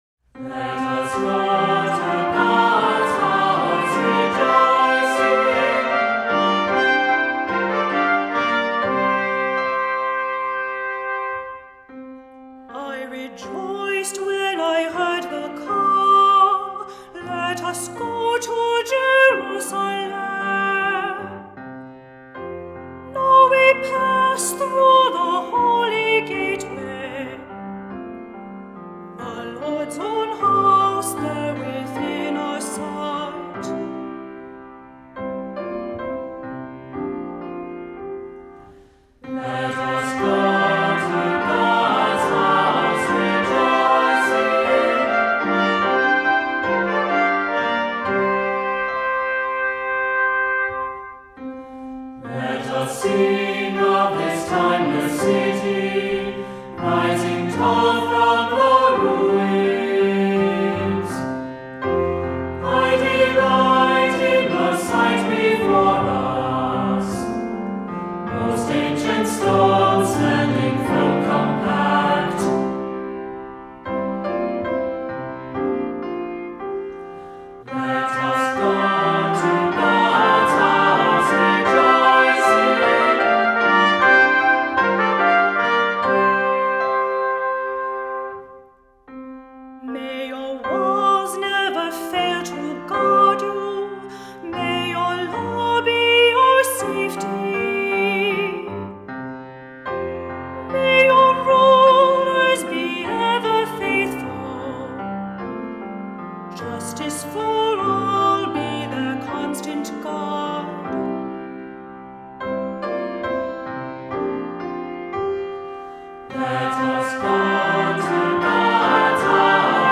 Voicing: 3-part Choir,Assembly,Cantor